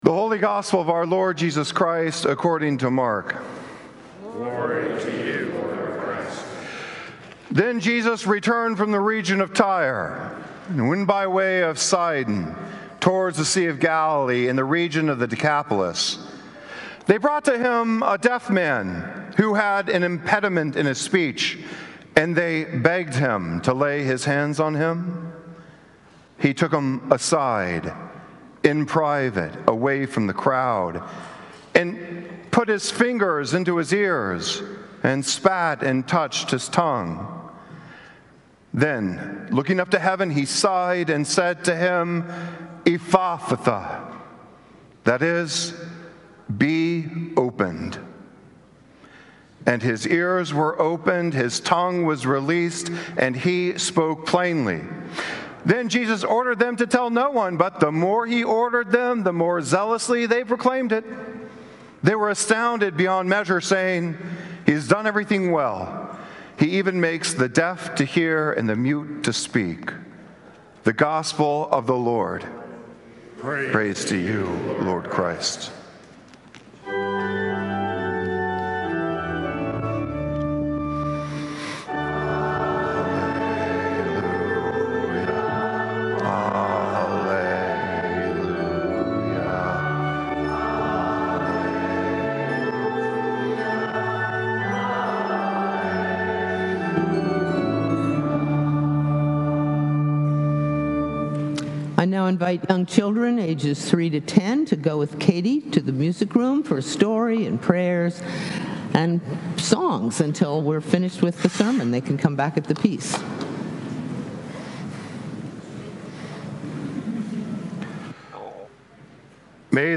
Sunday Sermon
Sermons from St. Columba's in Washington, D.C.